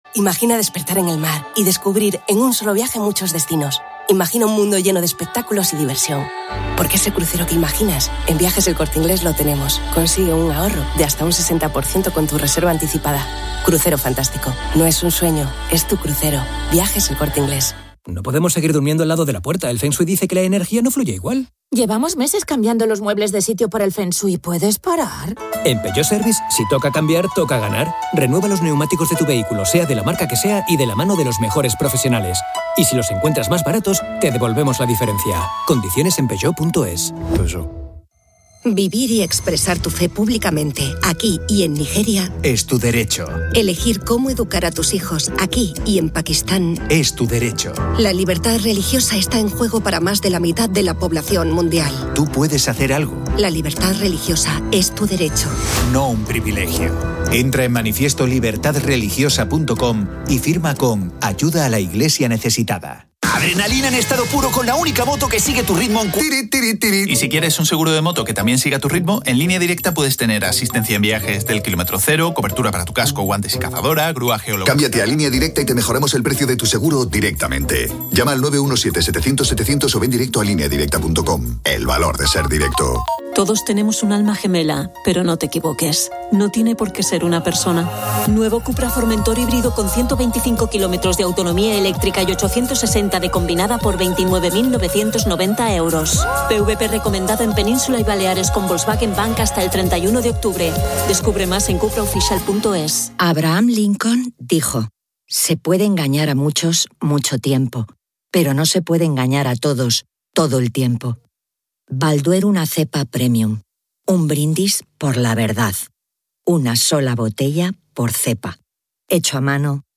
Un corresponsal de COPE informa desde la frontera entre Israel y Gaza sobre la devastación en el Kibbutz Nir Oz y la tensión, con sonidos de actividad militar.